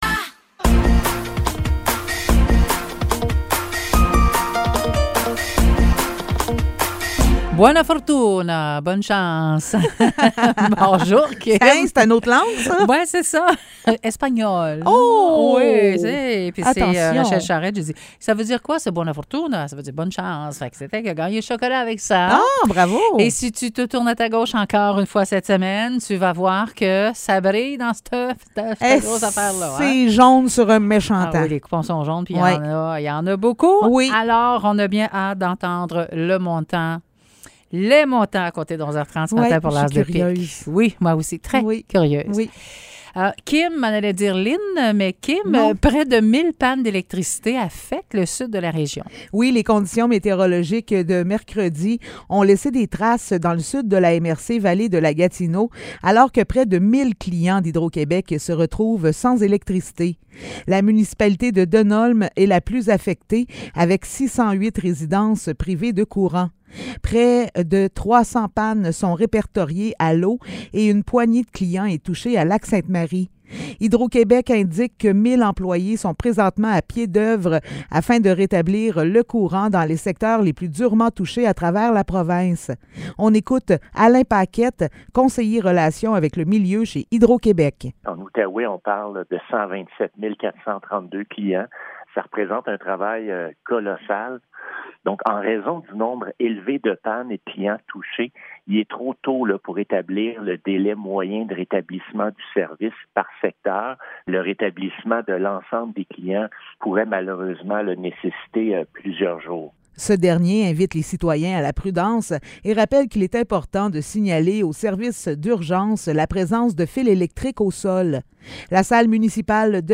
Nouvelles locales - 6 avril 2023 - 10 h